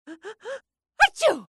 جلوه های صوتی
دانلود صدای عطسه زن 2 از ساعد نیوز با لینک مستقیم و کیفیت بالا